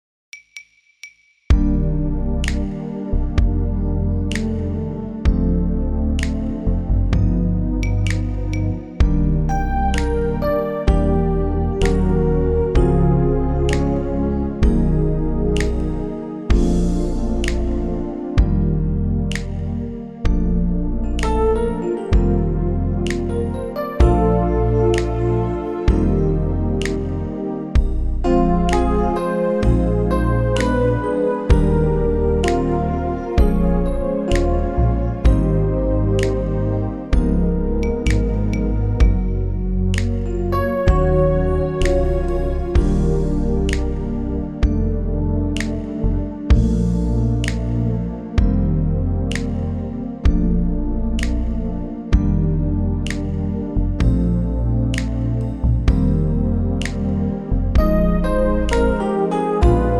SOLO SYNTH REMOVED!
MP3 NO SOLO DEMO:
key - Bb - vocal range - Bb to C
Super smooth arrangement